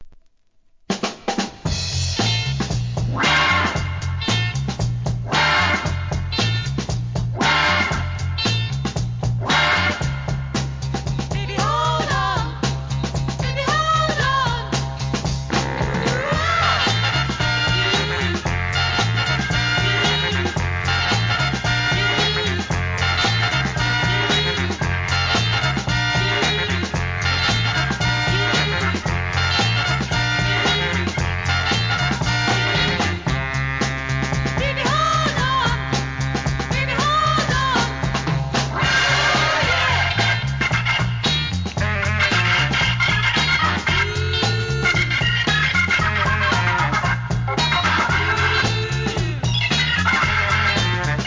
¥ 1,980 税込 関連カテゴリ SOUL/FUNK/etc...